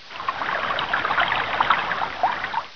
waterflow.wav